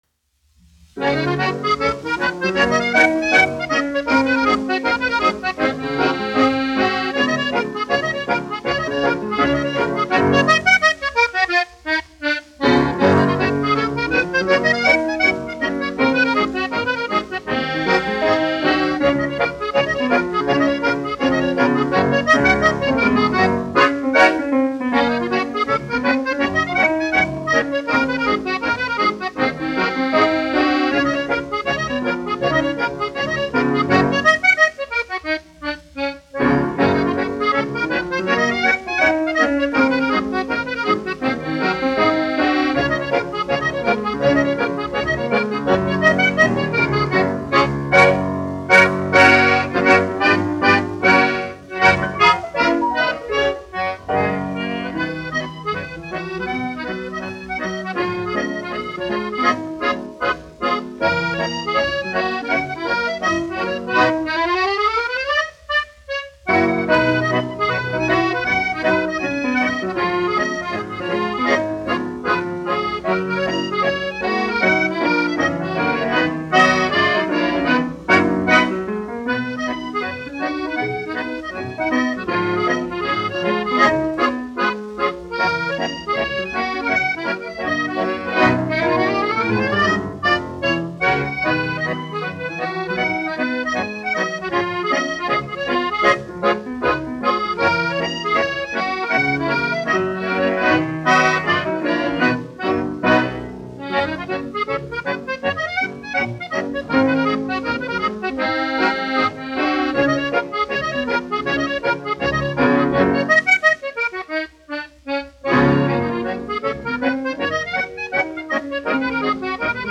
1 skpl. : analogs, 78 apgr/min, mono ; 25 cm
Fokstroti
Populārā instrumentālā mūzika
Akordeona un klavieru mūzika
Skaņuplate